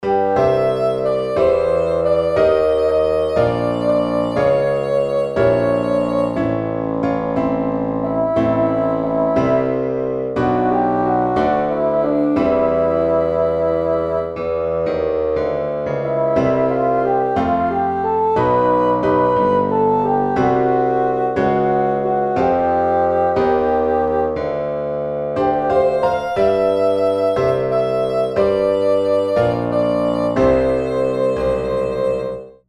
86 bpm
Wird aber nur ein Protoyp, billigste Piano-Akkorde + Gesang